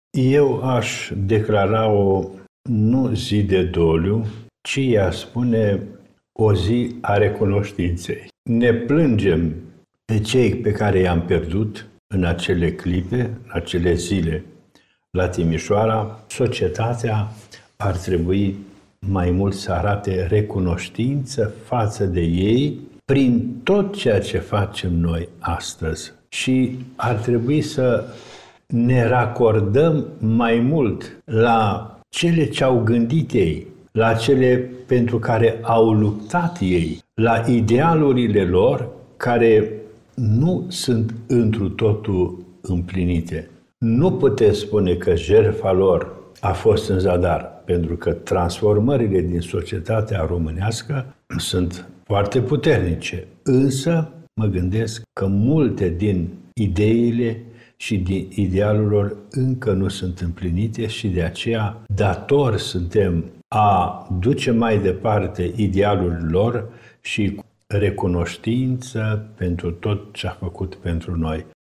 Înaltpreasfinția Sa Ioan Selejan, Mitropolitul Banatului, a transmis un mesaj de reflecție și recunoștință față de cei care și-au pierdut viața pentru libertate în decembrie 1989.